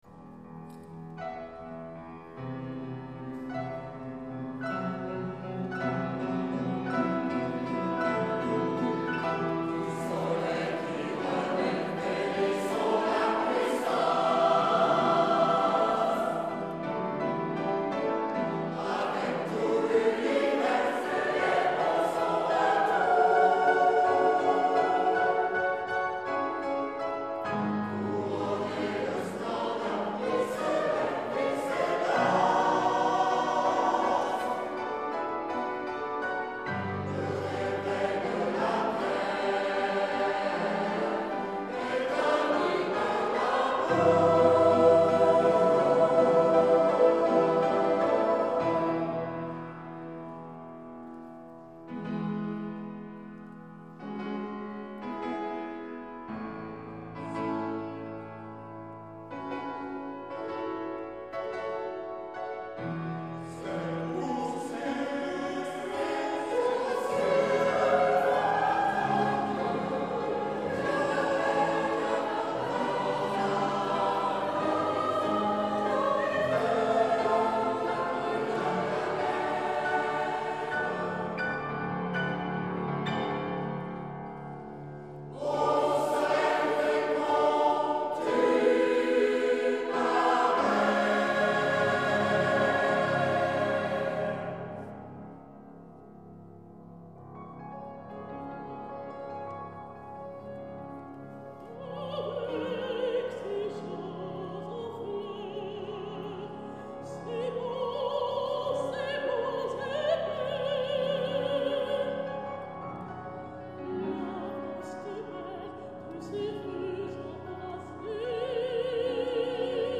Enregistrements réalisés en public par Voix Si-Voix La - Tous droits réservés.
Ensemble Vocal Voix Si-Voix La
Piano
Eglise Ste Rosalie   26 juin 2016